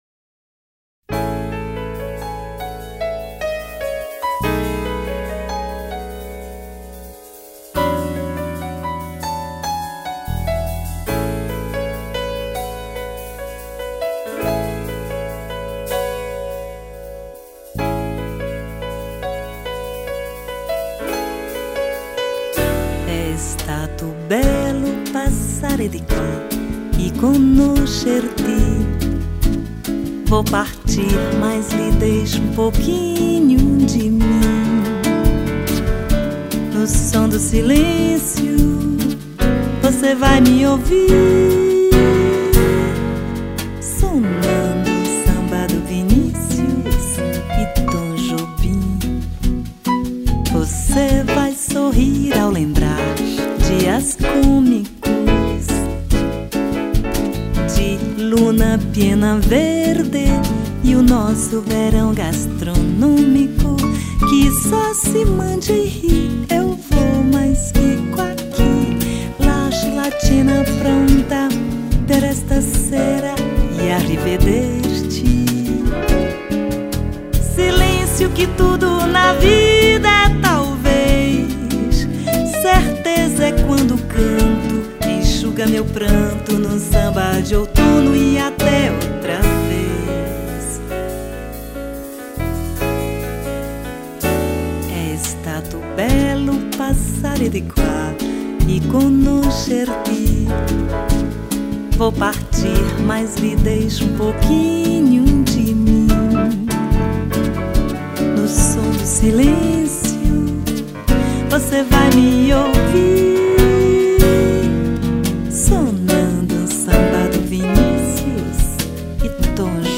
1571   03:44:00   Faixa:     Bossa nova
Baixo Elétrico 6
Bateria
Violao Acústico 6
Teclados